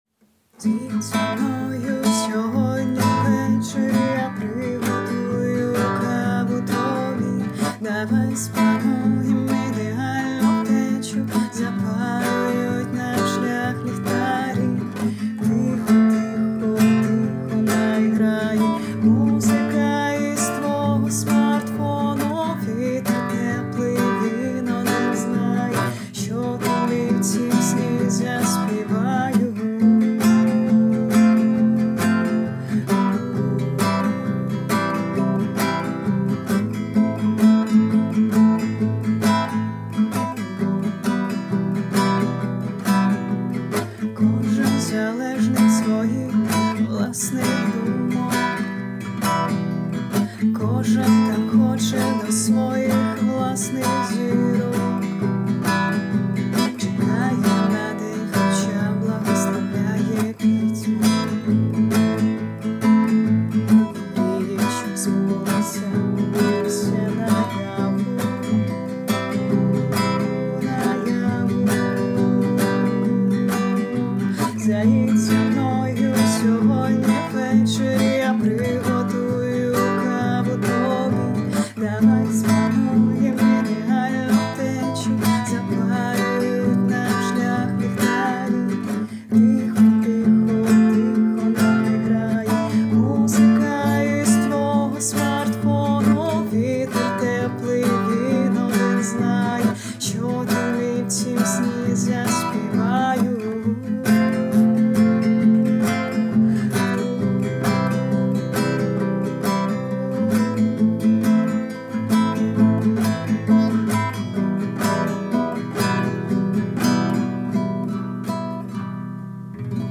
Колискова
ТИП: Музика
СТИЛЬОВІ ЖАНРИ: Ліричний